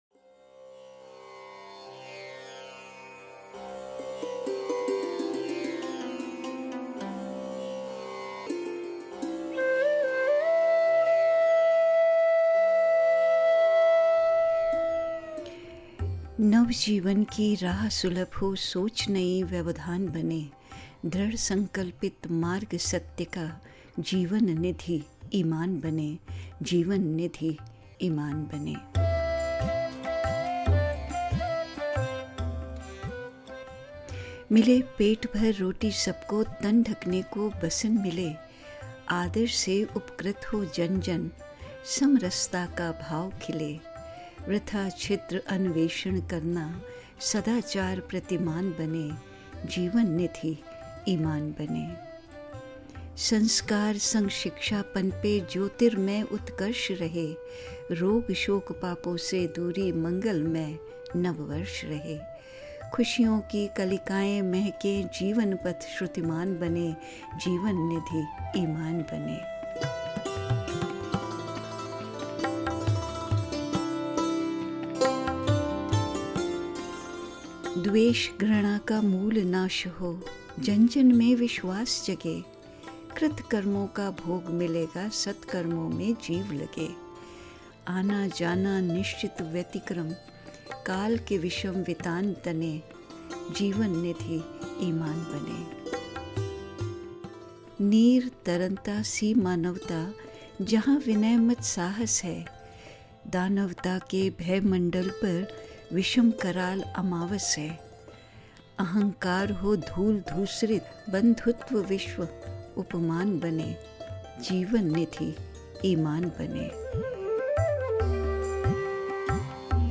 संगीतमय प्रस्तुति